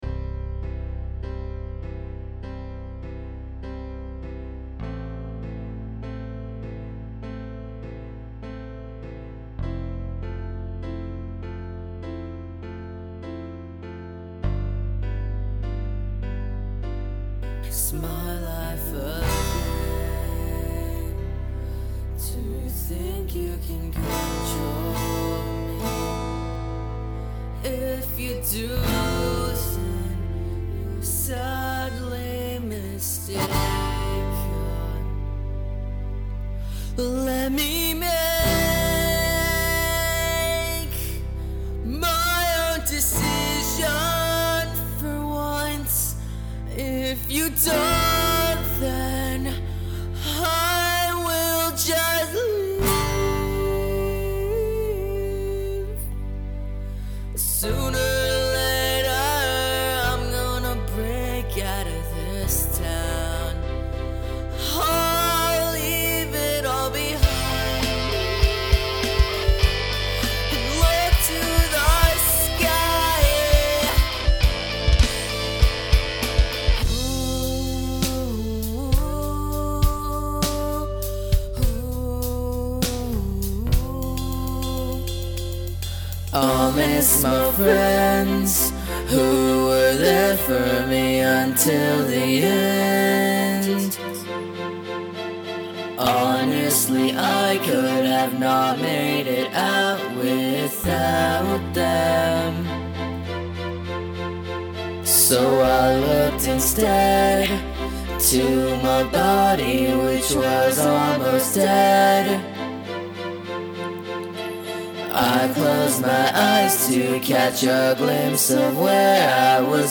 Rock/Hard-rock